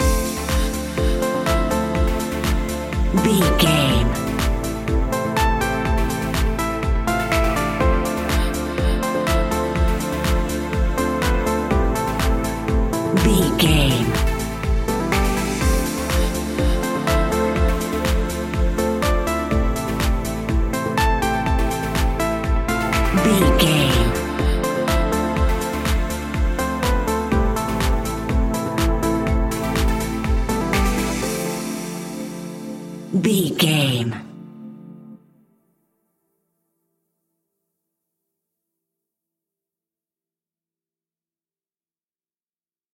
Aeolian/Minor
groovy
smooth
uplifting
drum machine
electro house
funky house
instrumentals
synth drums
synth leads
synth bass